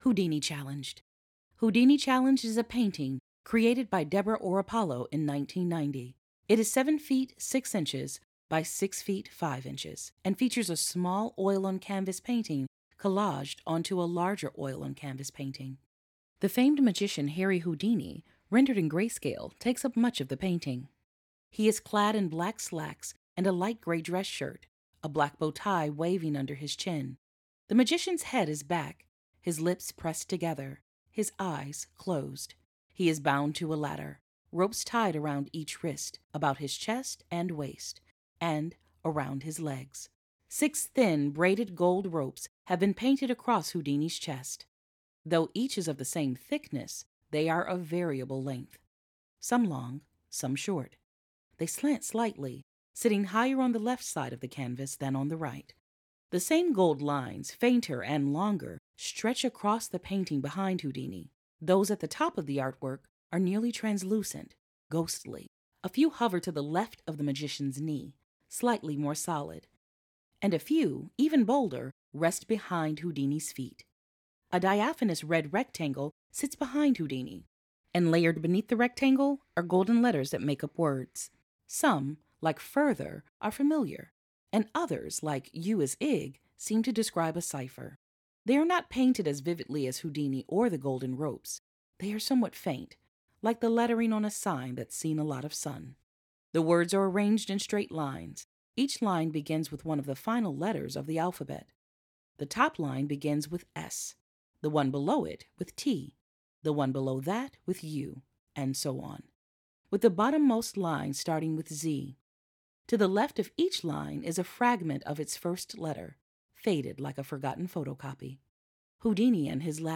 Audio Description (02:19)